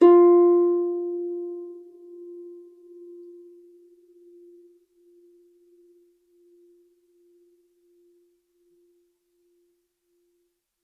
KSHarp_F4_mf.wav